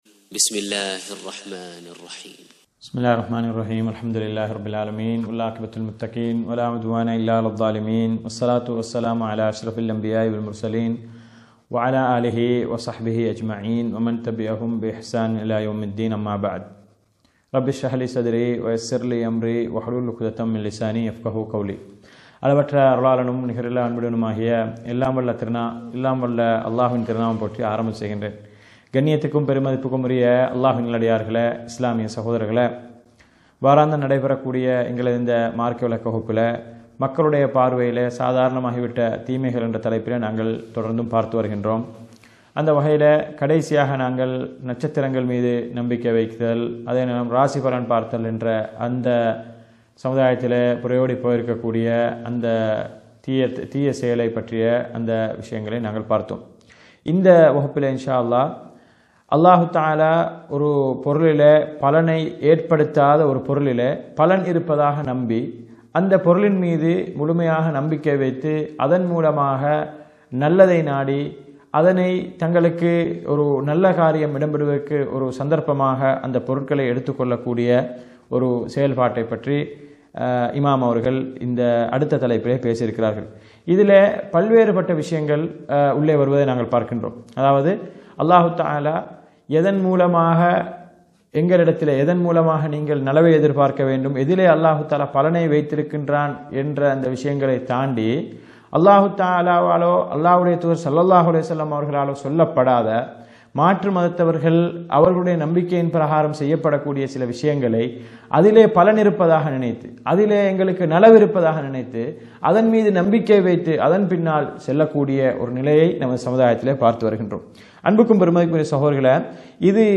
அஷ்ஷைக் முஹம்மத் ஸாலிஹ் அல்-முனஜ்ஜித் அவர்களால் தொகுக்கப்பட்ட ‘மக்களின் பார்வையில் சாதாரணமாகிவிட்ட தீமைகள்! எச்சரிக்கை!! என்ற நூலின் விளக்கவுரை!